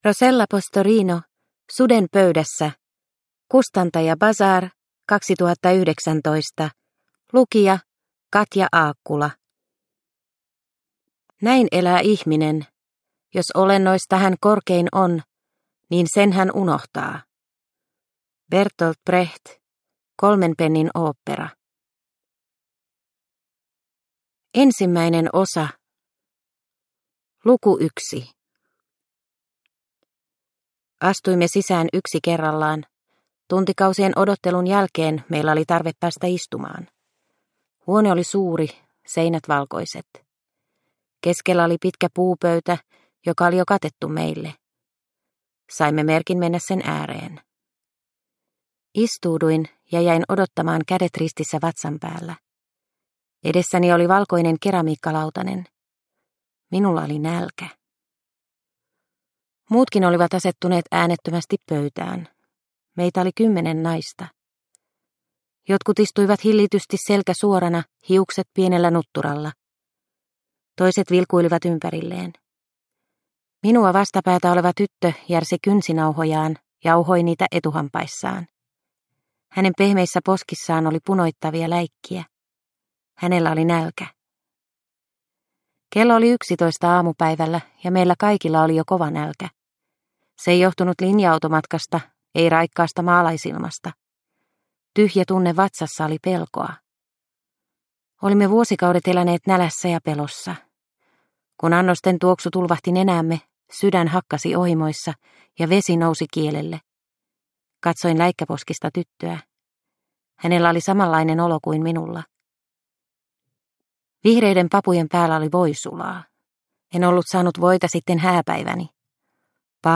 Suden pöydässä – Ljudbok – Laddas ner